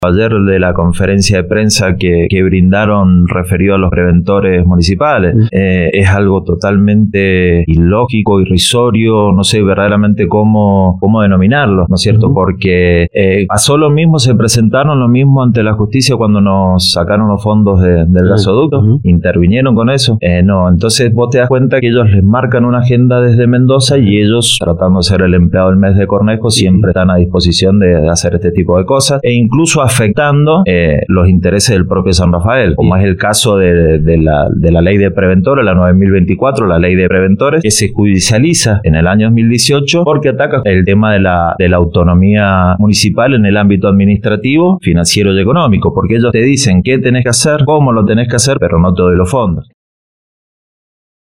mariano-camara-03-concejal-pj-criticas-a-la-ley-de-preventores-san-rafael-denuncia-ataque-a-la-autonomia-municipal.mp3